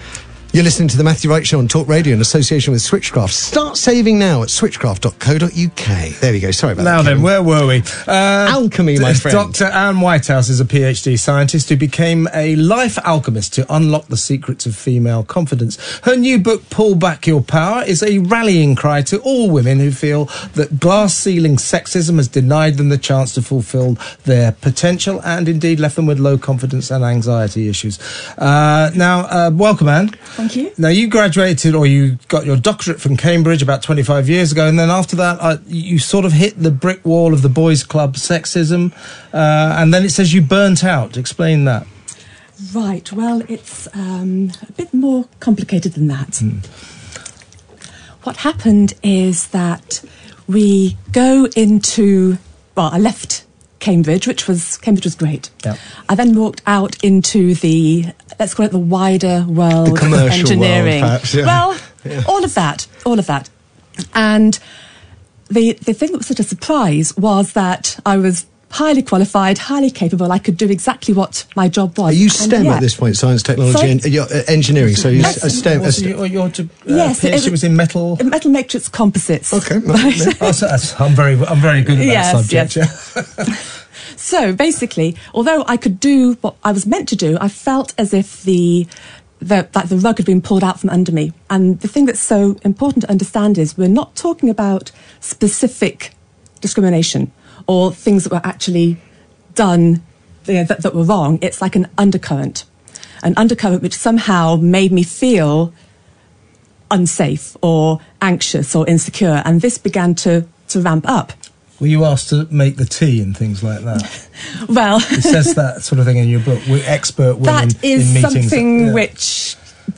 Talk Radio interview on the Matthew Wright show
Interview with Matthew Wright, Talk Radio